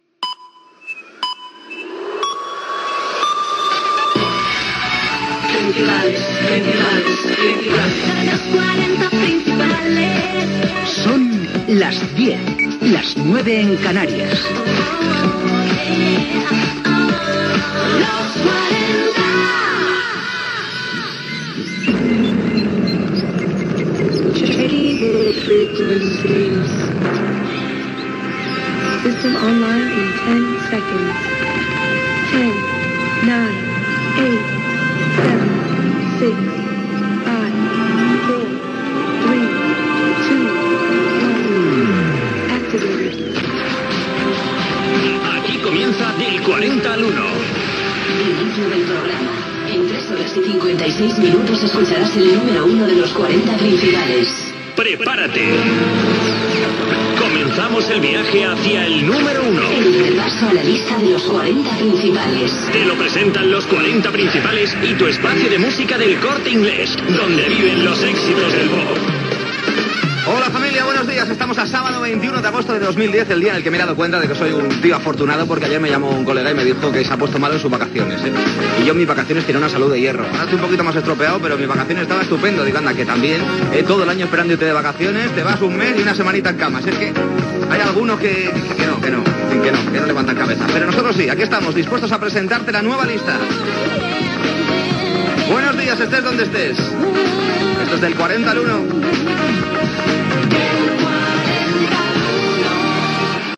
Hora, indicatiu de la ràdio, careta del programa, publicitat, comentari sobre un amic malalt, salutació
Musical
FM